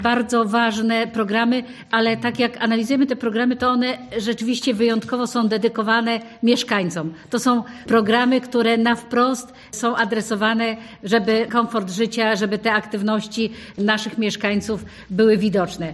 Zauważyliśmy, że programy, które realizujemy są przeznaczone głównie dla polepszenia życia społeczności mówiła podczas konferencji prasowej Elżbieta Lanc: